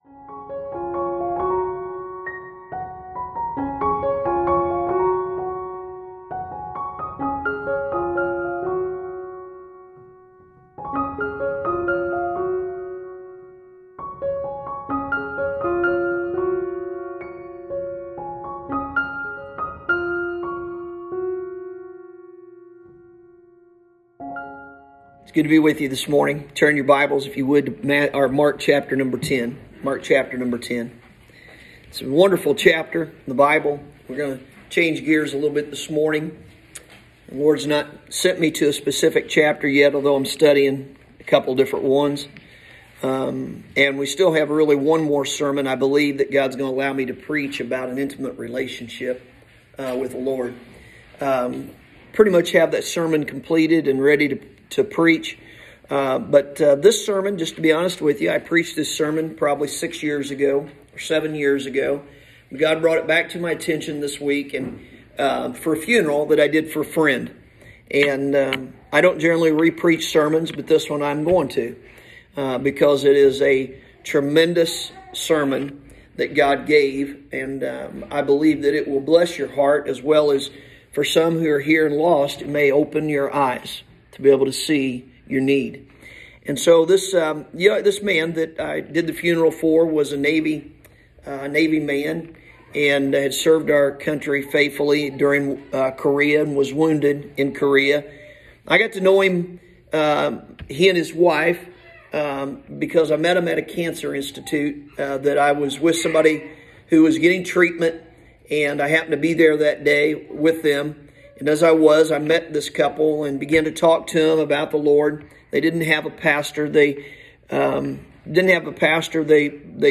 Sunday Morning – September 5th, 2021